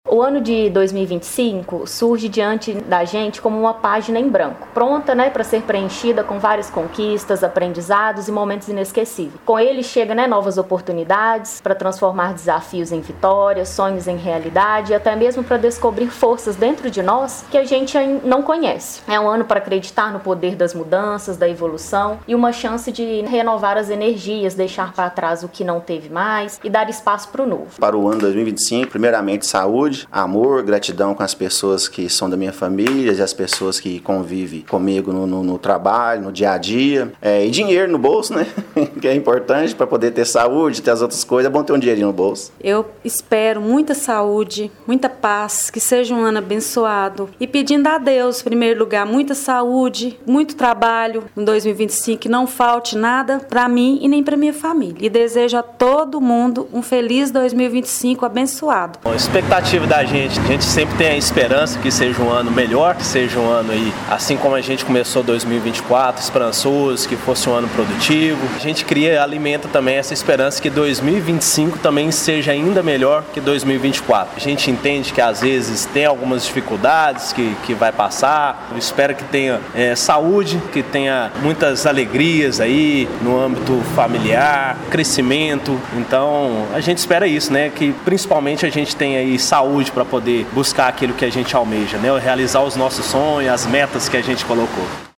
O Jornal da Manhã foi às ruas para saber quais são as expectativas dos paraminenses para 2025.